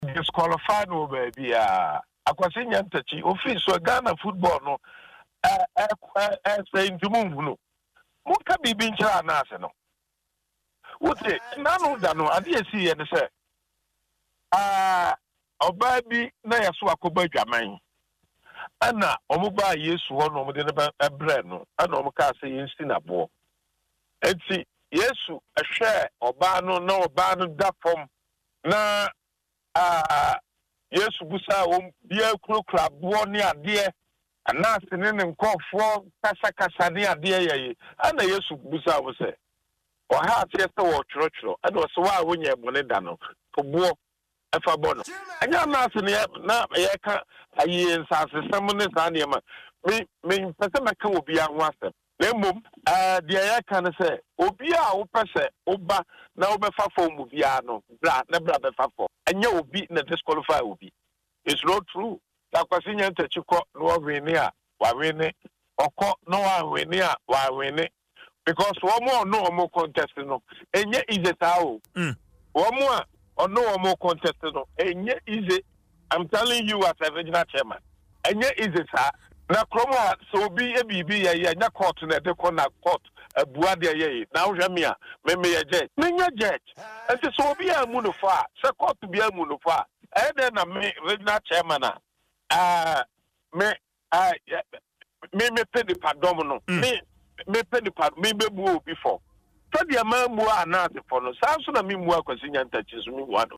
Chairman Wontumi made this known in an interview on Adom FM’s morning show, Dwaso Nsem on Wednesday, March 3, 2024.